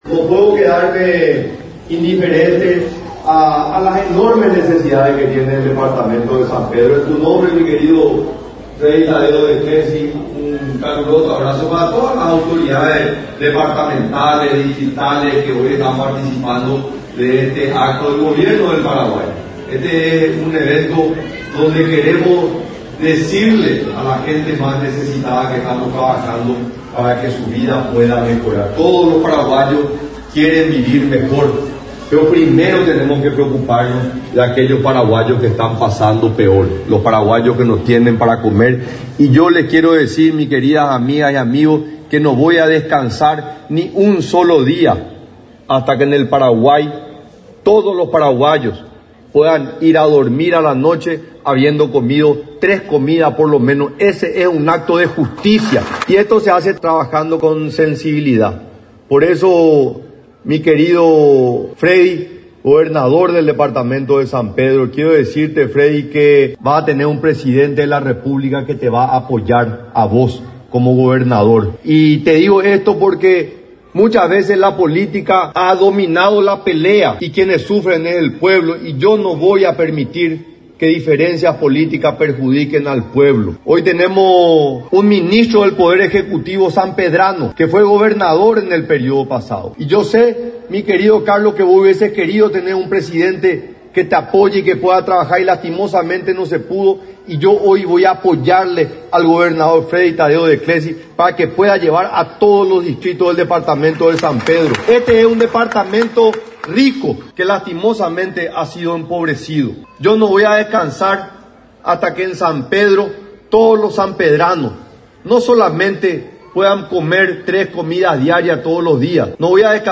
El pasado viernes en la capital de San Pedro, se llevó a cabo el acto de entrega de una cantidad de 930 tarjetas de débito a participantes del programa Tekoporâ Mbarete del Ministerio de Desarrollo Social.
En la oportunidad, el mandatario se dirigió a los presentes, asegurando que el Gobierno está trabajando para mejorar la calidad de familias en situación de vulnerabilidad.
EDITADO-1-PRESIDENTE-SANTIAGO-PENA.mp3